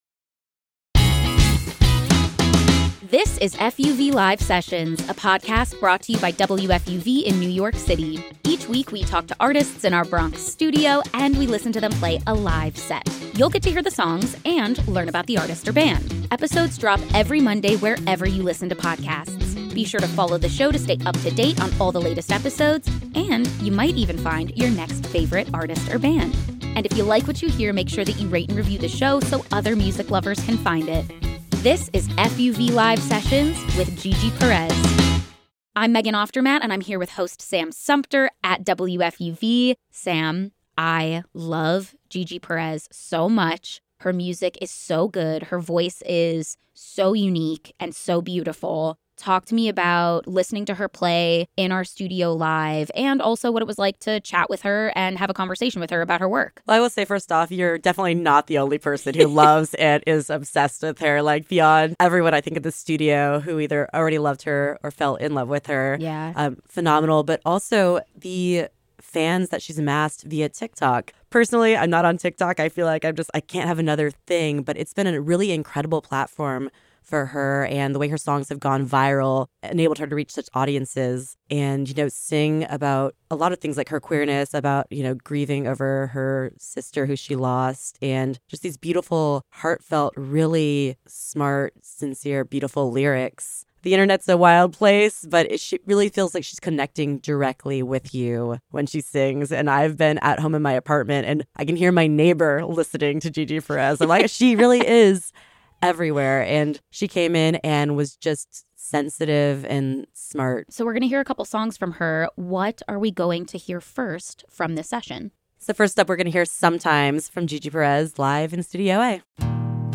Singer-songwriter